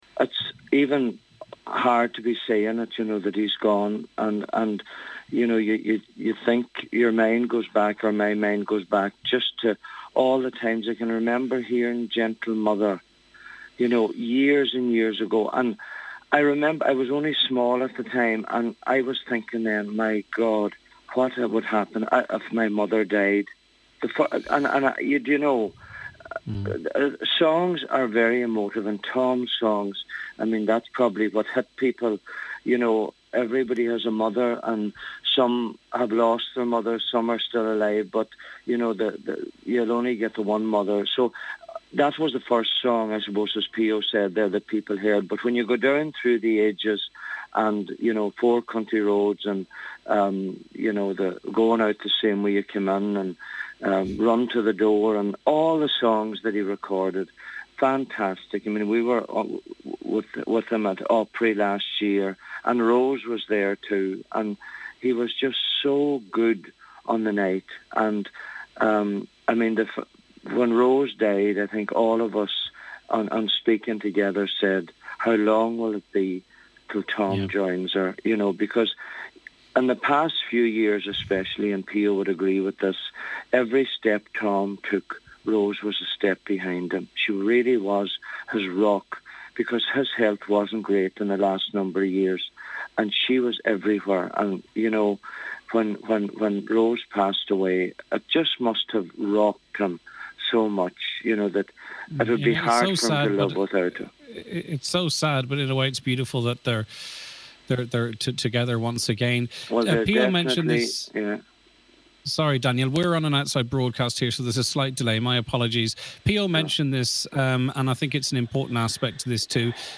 Singer Daniel O’Donnell told today’s Nine he’s a huge loss to the country music scene: